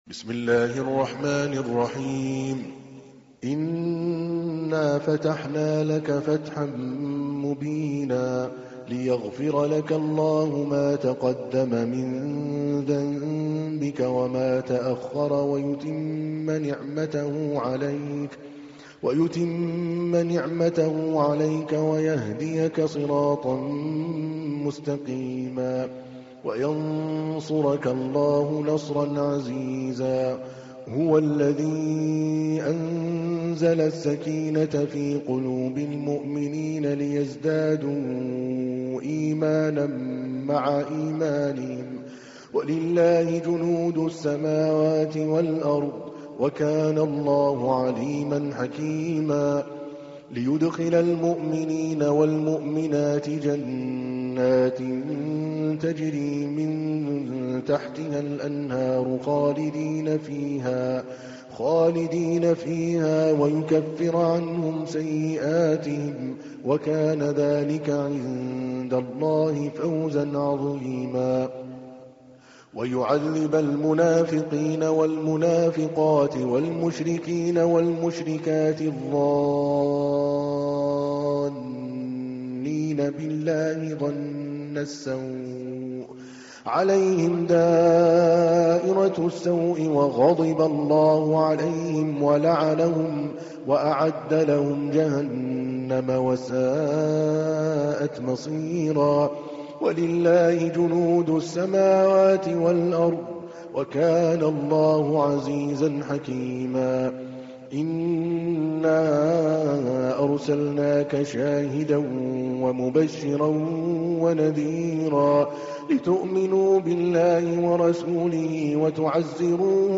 تحميل : 48. سورة الفتح / القارئ عادل الكلباني / القرآن الكريم / موقع يا حسين